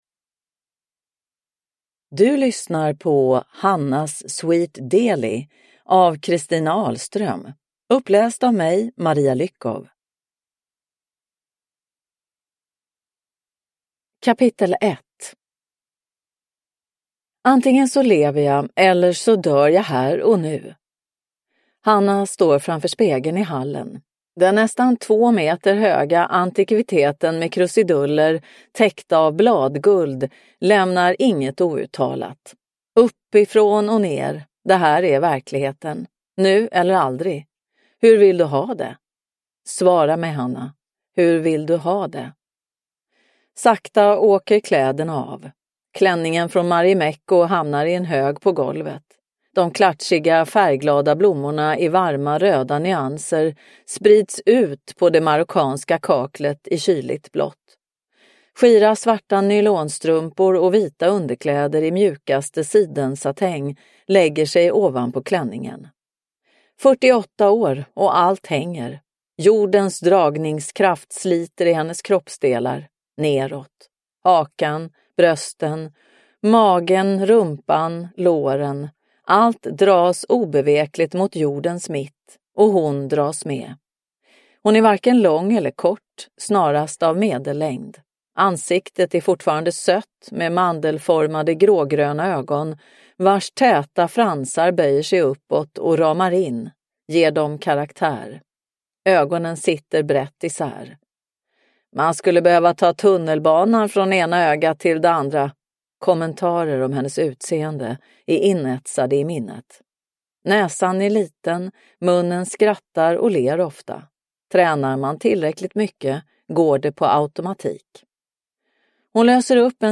Hannas Sweet Deli – Ljudbok – Laddas ner